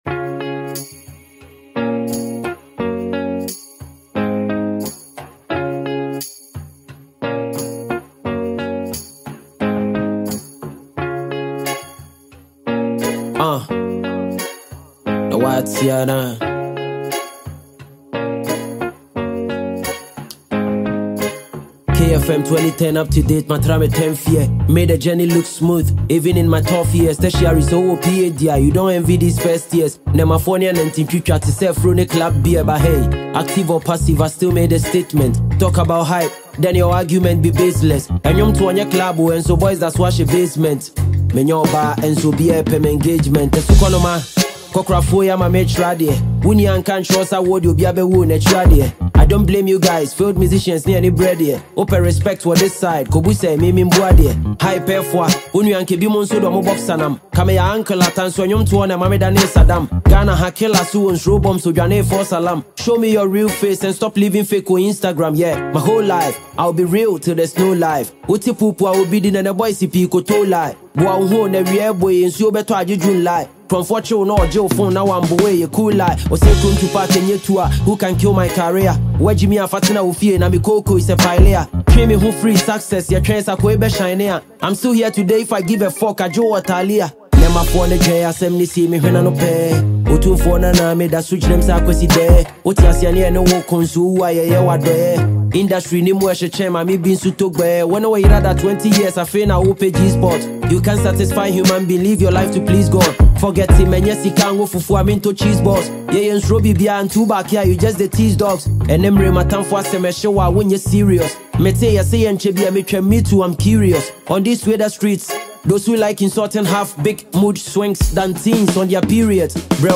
Multiple award-winning Ghanaian rapper